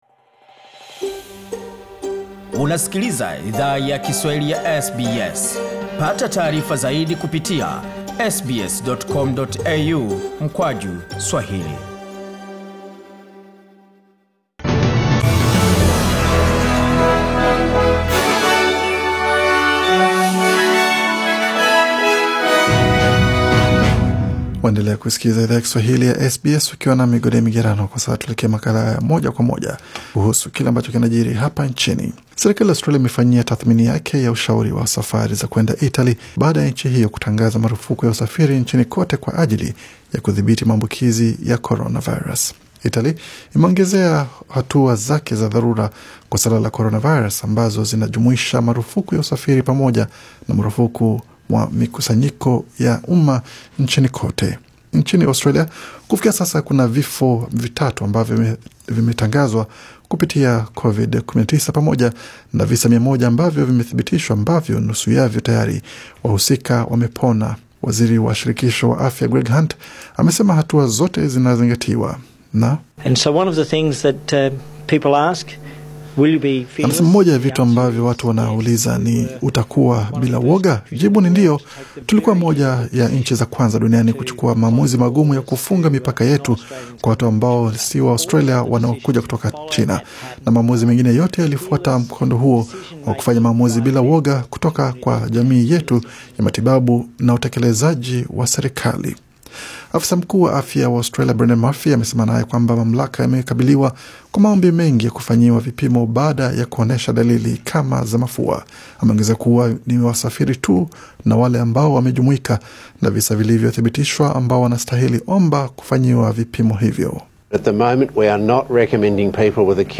Taarifa za habari: Mamlaka wa Australia, wakabiliwa kwa ongezeko la ombi la vipimo vya Coronavirus